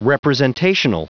Prononciation du mot representational en anglais (fichier audio)